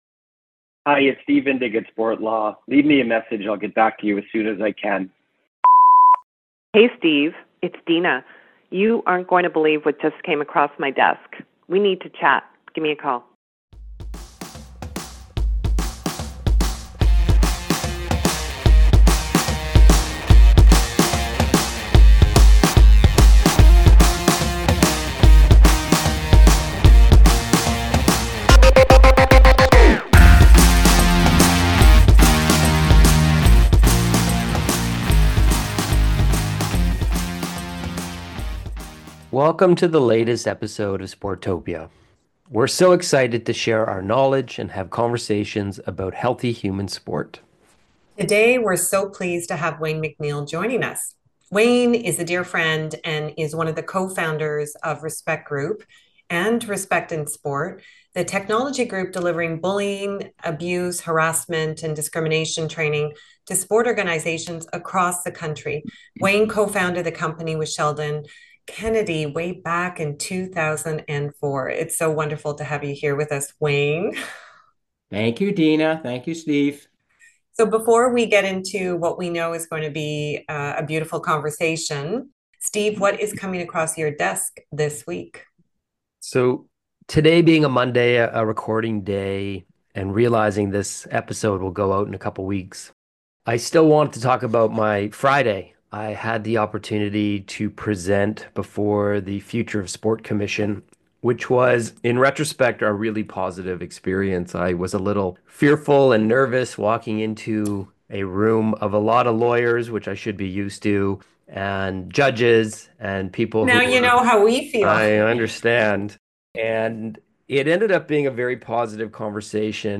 Respect in Sport (RIS) is a technology-based group that delivers bullying, abuse, harassment and discrimination training to sport organizations across the country. Listen in as the trio discuss the Legacy of RIS over the last 20 years and their biggest hope for the future of sport.